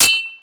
hitKnife.mp3